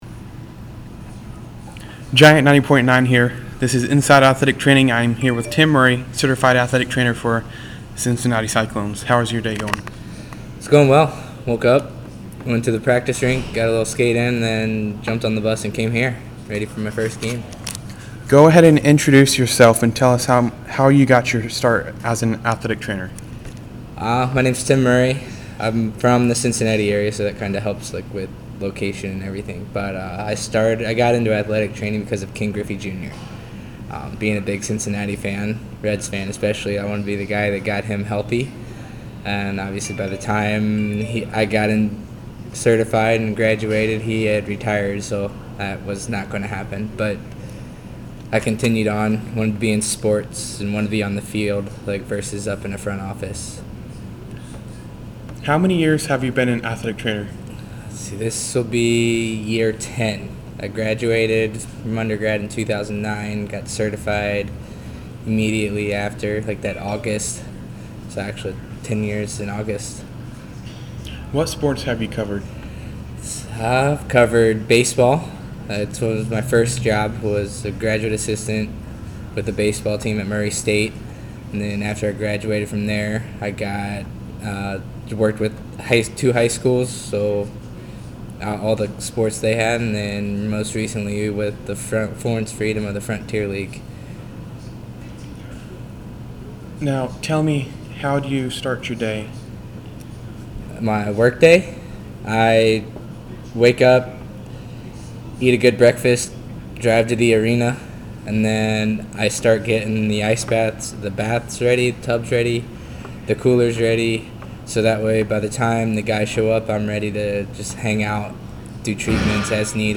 Inside Athletic Training Interview